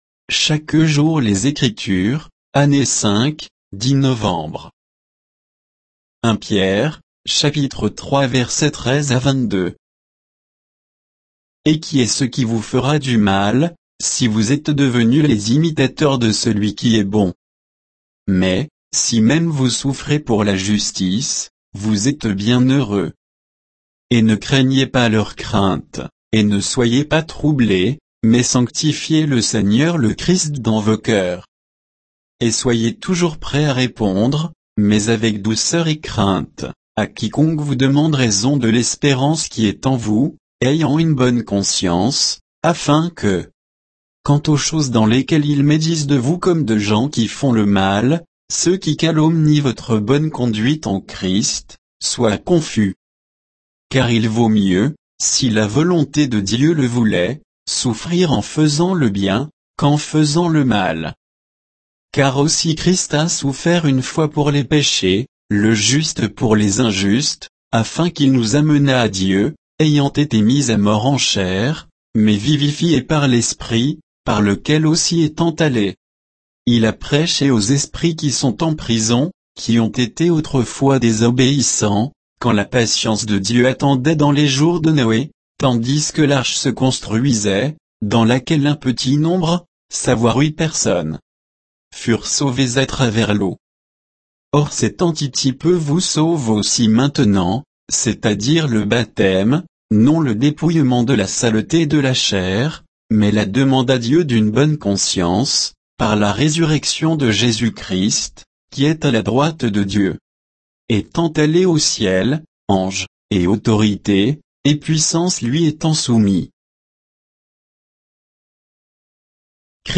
Méditation quoditienne de Chaque jour les Écritures sur 1 Pierre 3